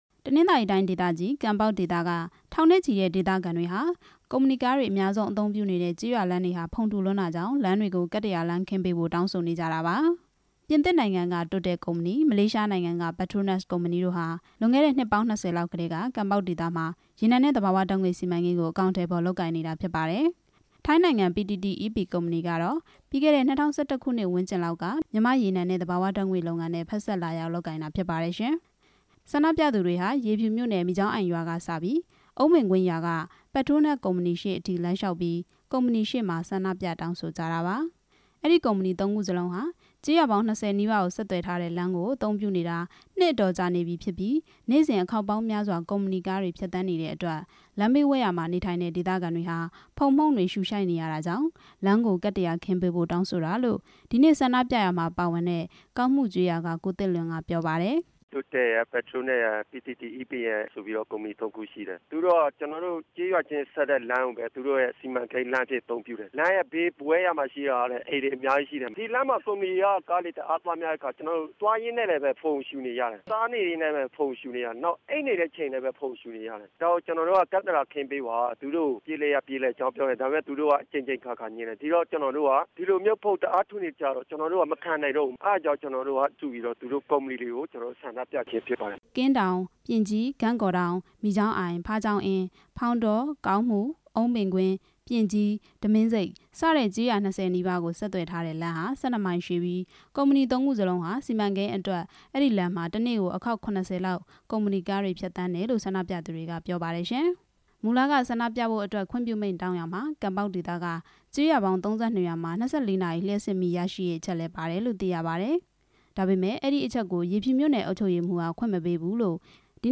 ဆန္ဒပြပွဲအကြောင်း တင်ပြချက်